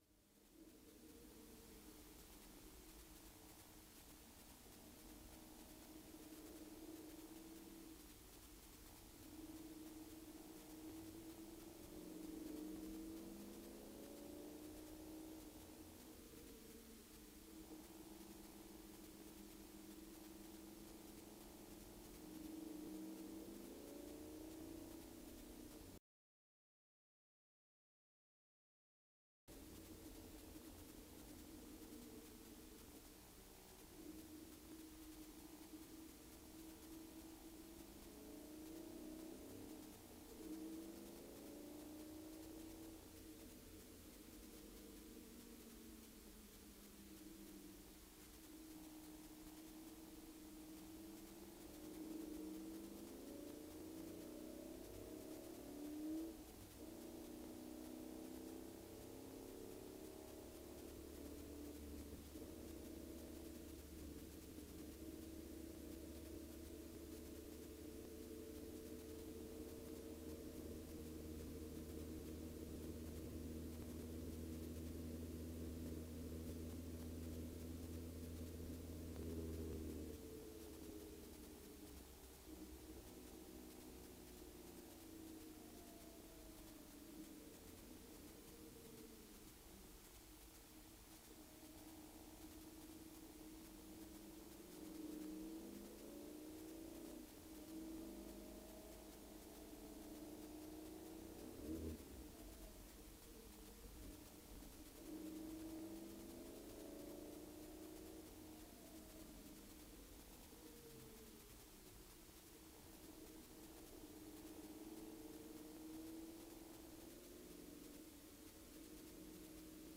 200６年２月２６日　富士スピードウェイ
コースコンディション　：　ウェット
あいにくの雨です．．しかも、結構降っていました。
音声無しです。（マイクの電源を入れ忘れました）